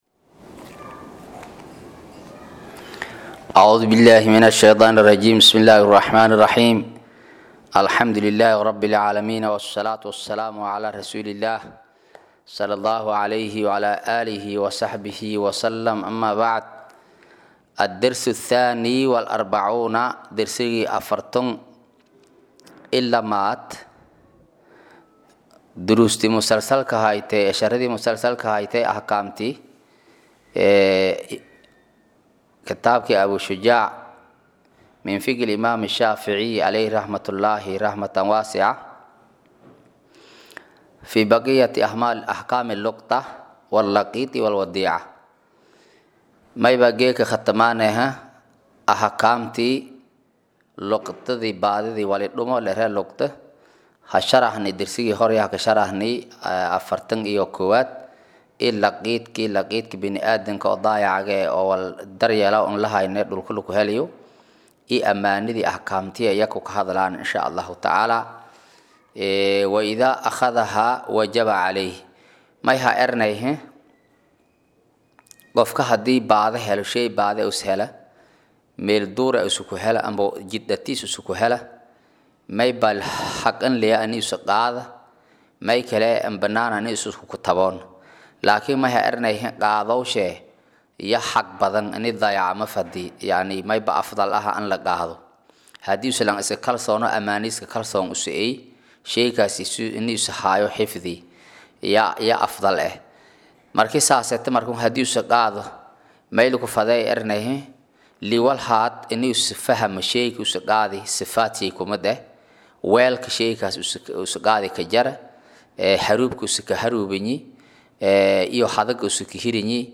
Maqal- Casharka Abuu Shujaac: Dasrsiga 42aad
Casharka-42_Kitaabka-Abuu-Shujaac.mp3